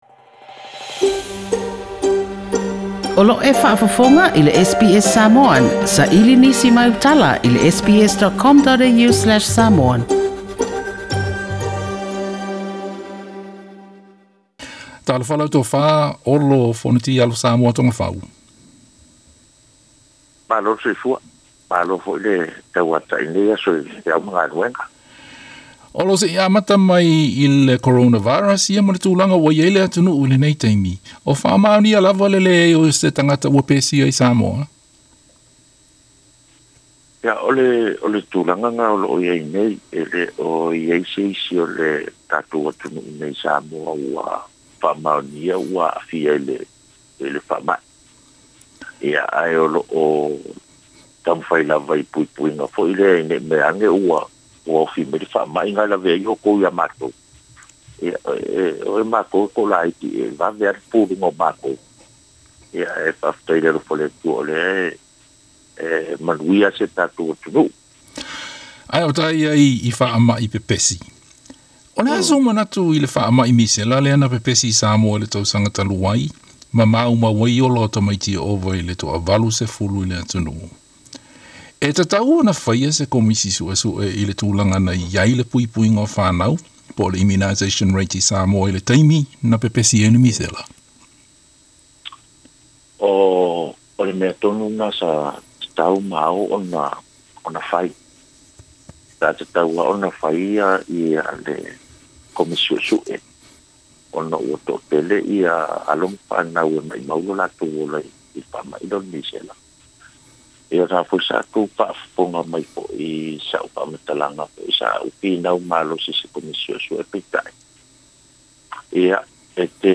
Talanoaga ma le Tofa 'Olo Fonoti Alosamoa Togafau - sui faipule o Salega i Sisifo
Se talanoaga ma le sui faipule o Salega i Sisifo, le Tofa 'Olo Fonoti Alosamoa Togafau, o se tasi o sui tuto'atasi o le palemene o Samoa.